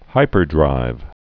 (hīpər-drīv)